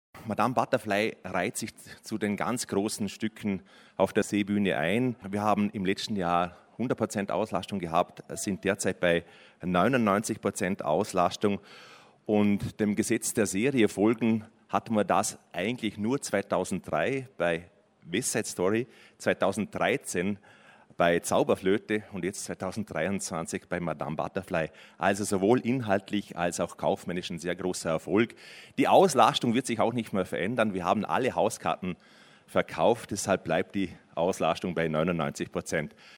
O-Ton Pressekonferenz Vorläufige Bilanz - News
bregenz_bilanz-2023-news.mp3